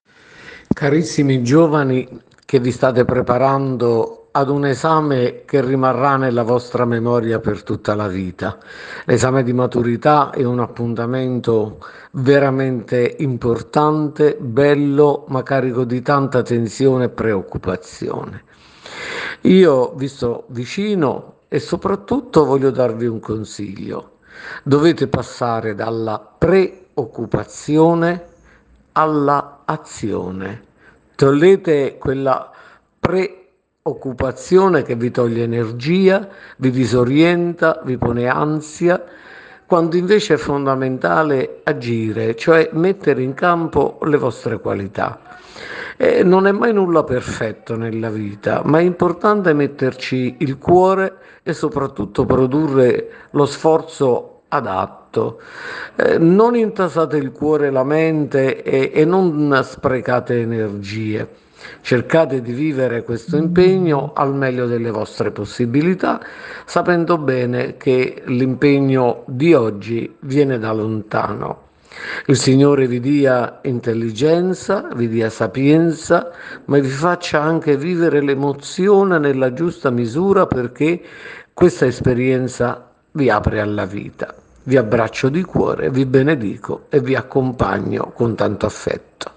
Io vi sono vicino e vi do un consiglio: dovete passare dalla preoccupazione all’azione… Ascolta l’audio messaggio del Vescovo.
messaggio_del_vescovo_ai_maturandi_diocesi_sessa_aurunca.ogg